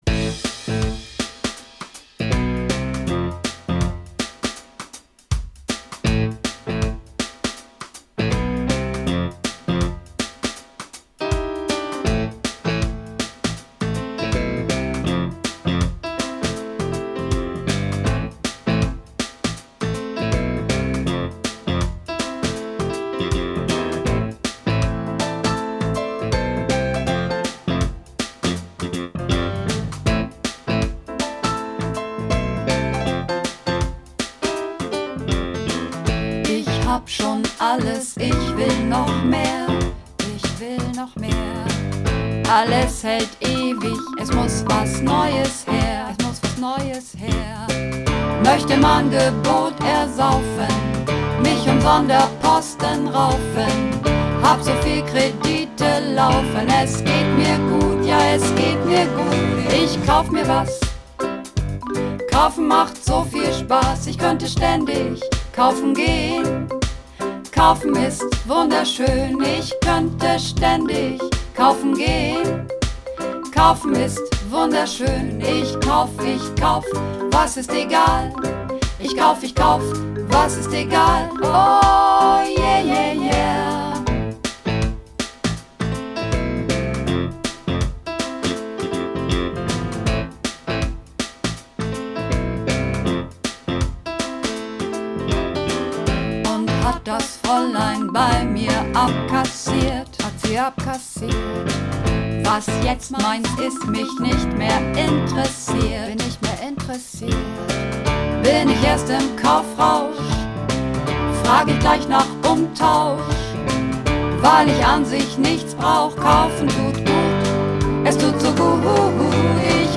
Übungsaufnahmen - Kaufen
Kaufen (Mehrstimmig - lang)